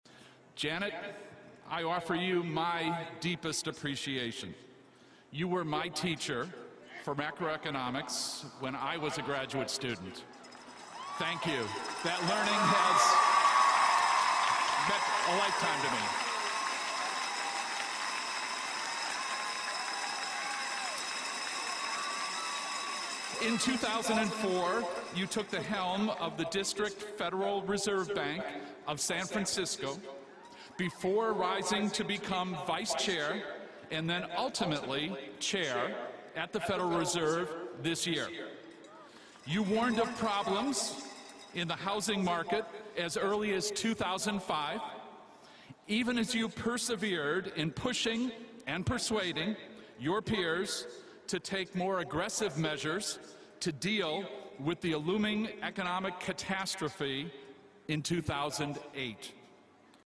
公众人物毕业演讲 第91期:耶伦纽约大学(2-1) 听力文件下载—在线英语听力室